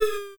Hit & Impact
Hit3.wav